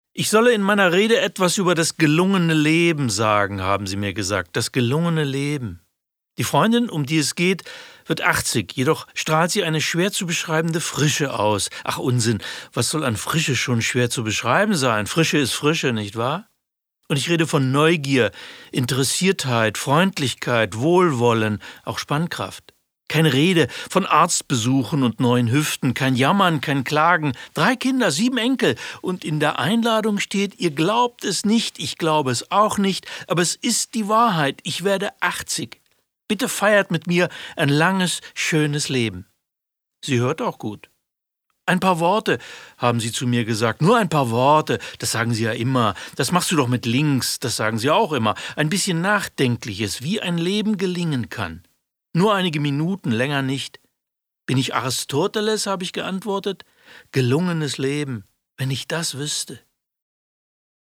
Wozu wir da sind. Walter Wemuts Handreichungen für ein gelungenes Leben (Ungekürzte Lesung von Axel Hacke)
axel-hacke-wozu-wir-da-sind-hoerprobe.mp3